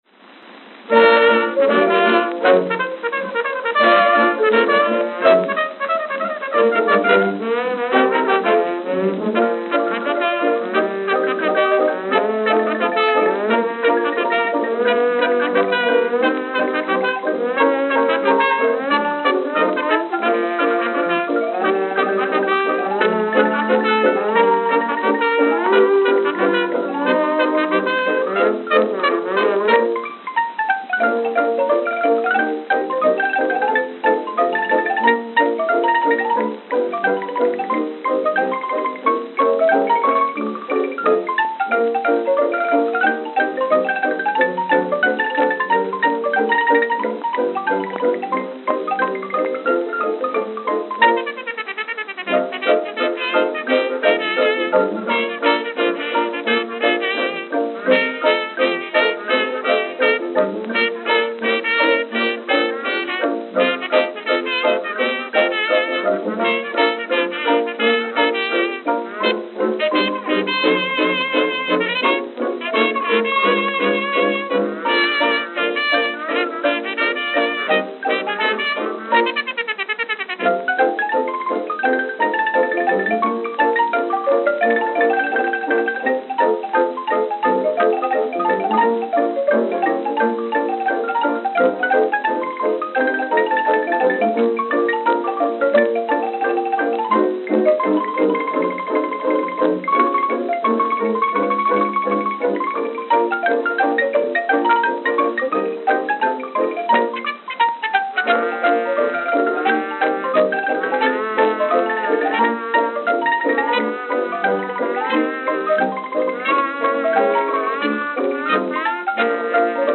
Note: Worn towards end.